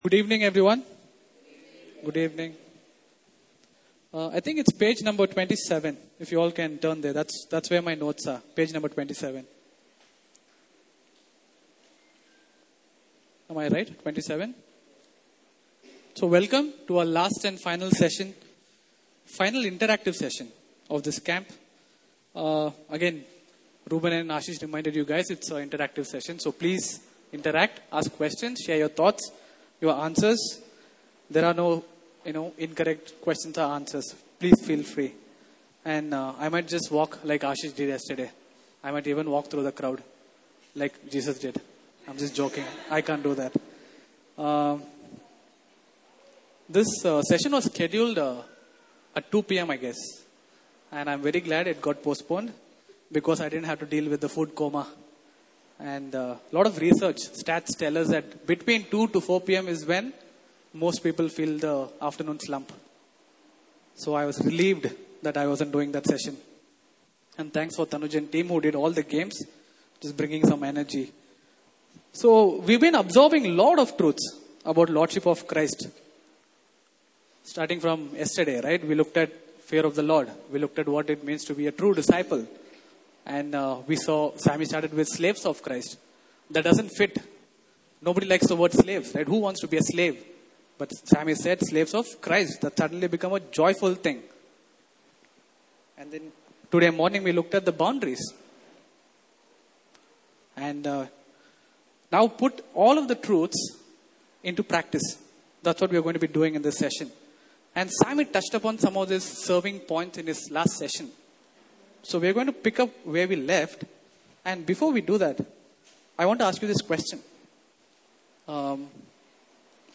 Service Type: Interactive Session